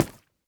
sounds / step / coral2.ogg
coral2.ogg